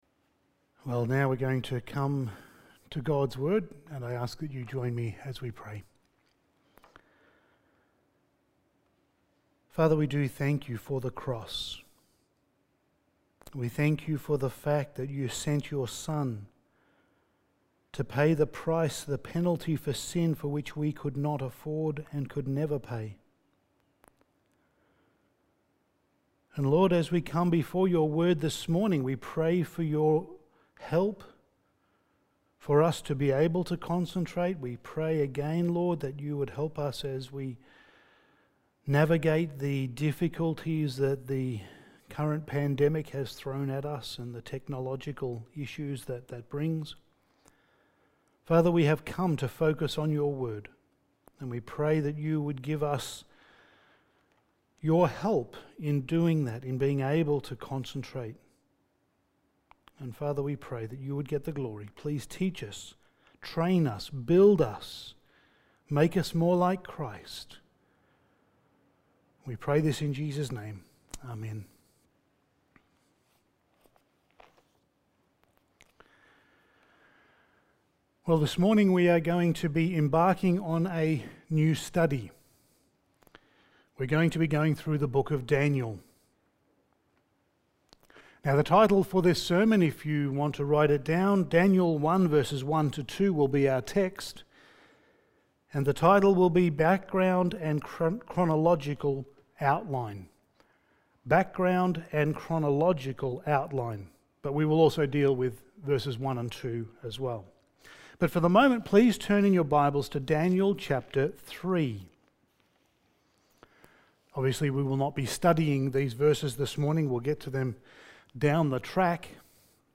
Passage: Daniel 1:1-2 Service Type: Sunday Morning